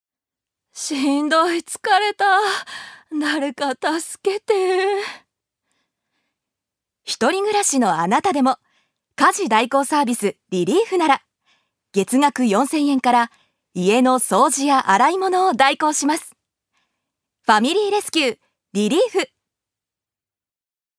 ジュニア：女性
ナレーション４